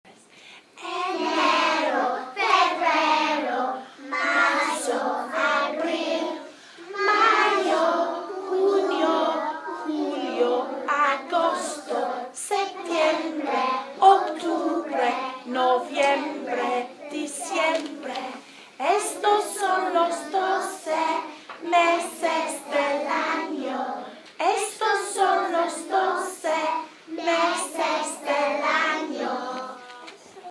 Hedgehogs class singing in Spanish